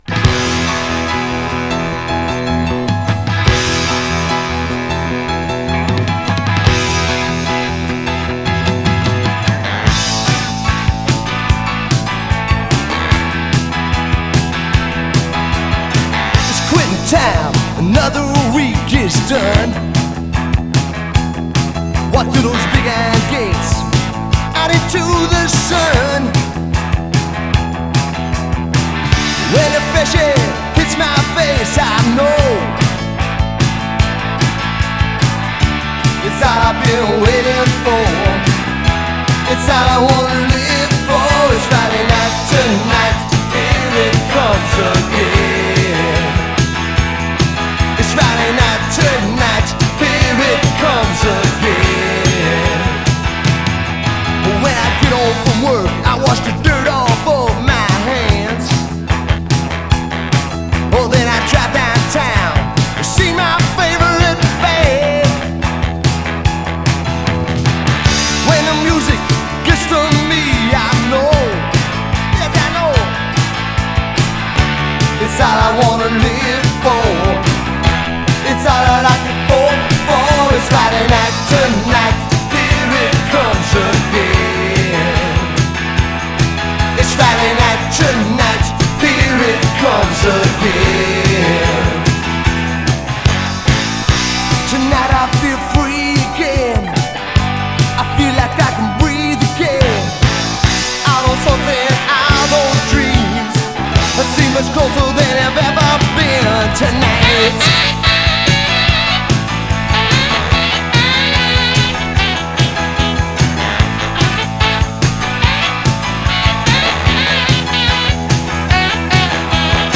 A great fist-pumping anthem to the end of the work week.
Category: Classic Rock, Friday Songs